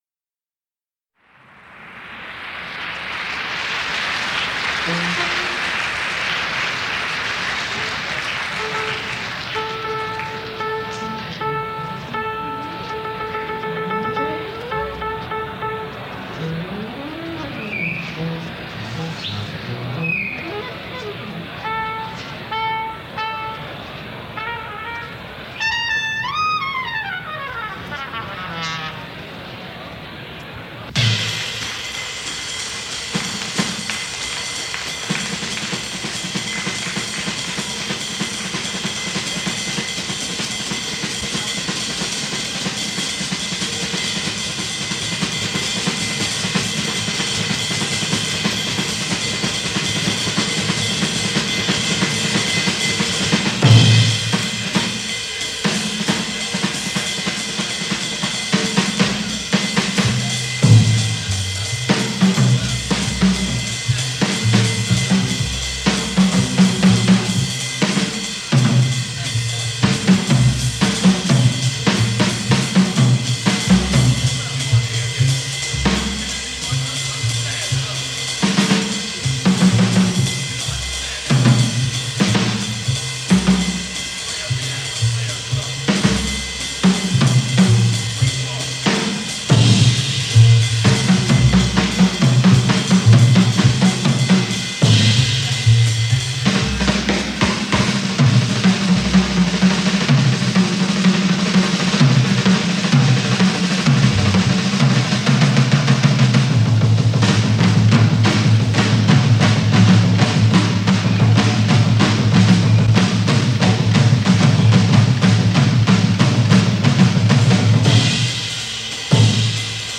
in concert from Zurich, Switerland